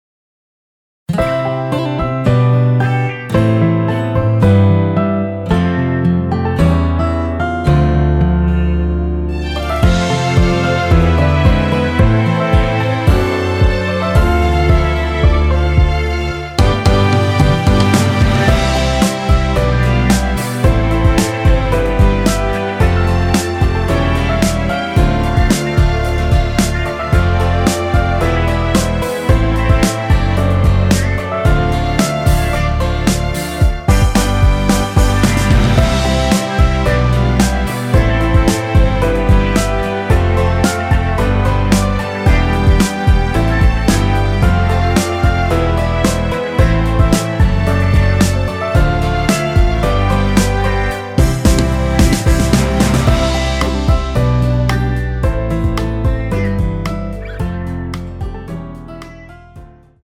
원키에서(-2)내린 MR입니다.
Eb
앞부분30초, 뒷부분30초씩 편집해서 올려 드리고 있습니다.
중간에 음이 끈어지고 다시 나오는 이유는
곡명 옆 (-1)은 반음 내림, (+1)은 반음 올림 입니다.